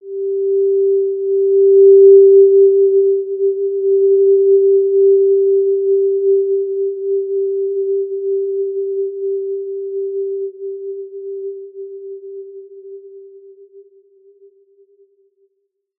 Basic-Tone-G4-mf.wav